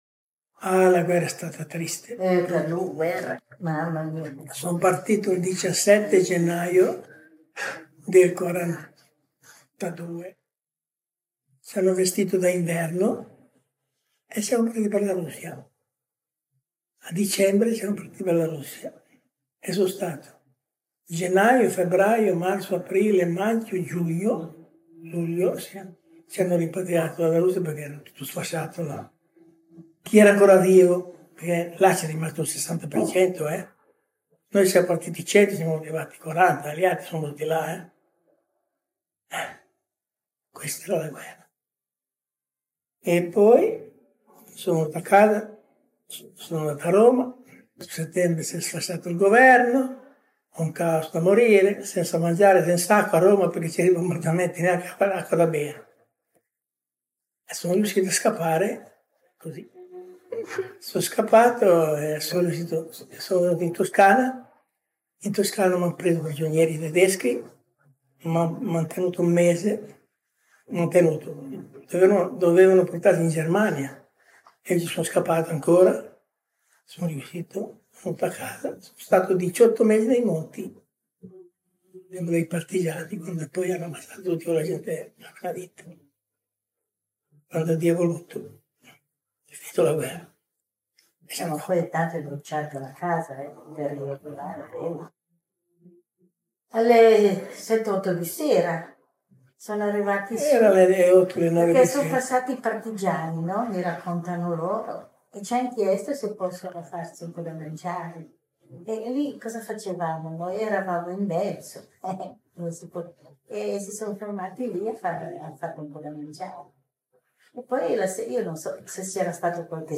Voci narranti